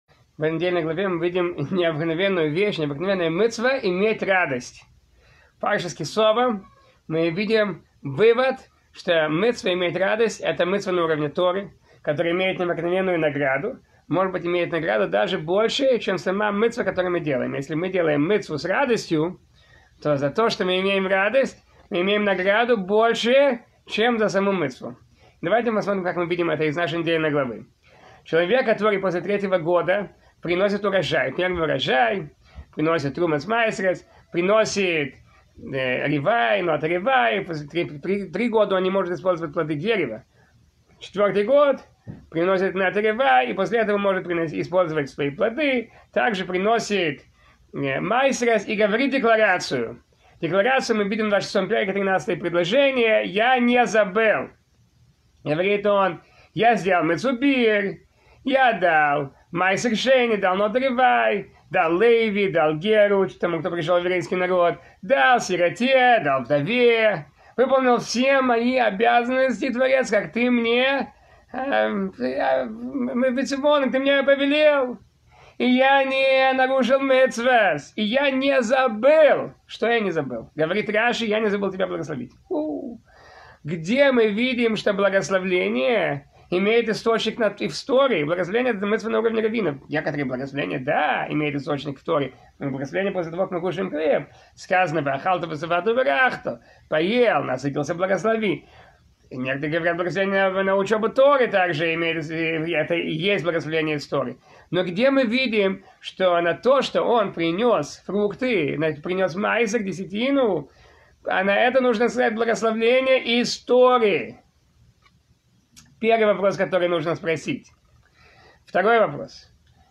Урок с отсылками на строки главы Ки Таво последней книги Пятикнижия, книги Дварим (Второзаконие)